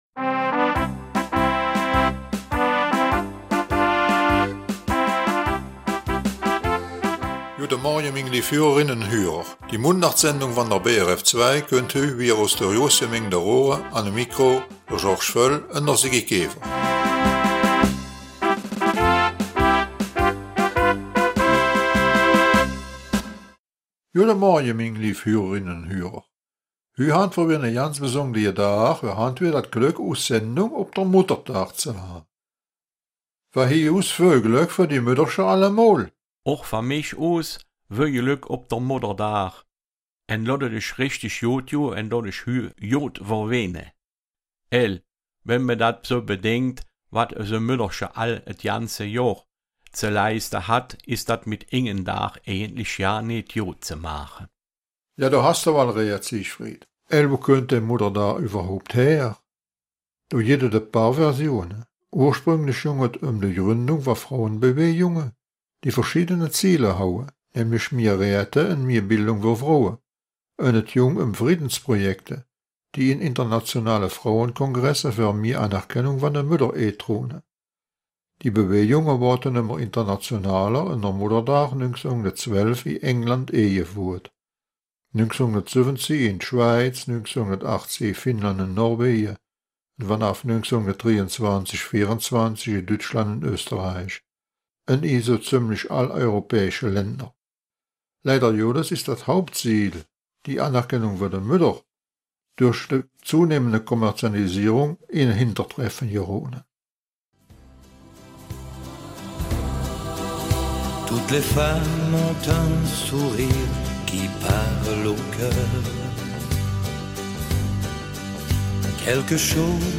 Raerener Mundart - 11. Mai
Die Mundartsendung vom 11. Mai aus Raeren bringt folgende Themen: